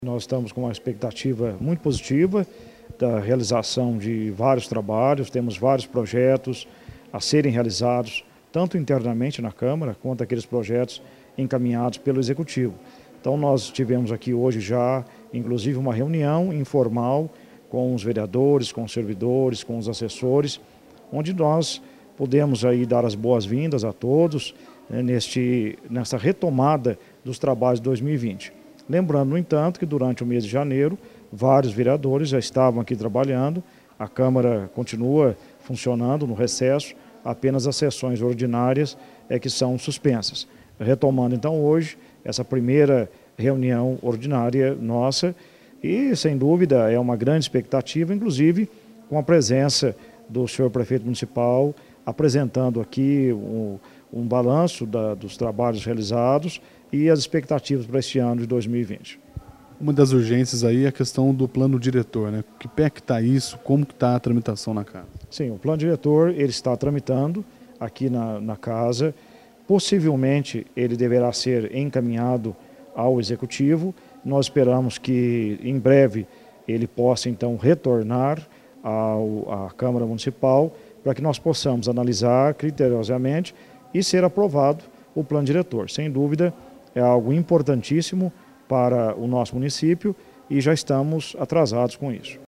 A primeira reunião ordinária da Câmara de Poços em 2020 foi realizada nesta terça-feira,04. O presidente da Casa, vereador Carlos Roberto de Oliveira Costa (PSC), destaca que a expectativa para o início dos trabalhos é muito positiva.